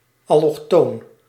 Ääntäminen
Tuntematon aksentti: IPA: /ɑlɔxˈtoːn/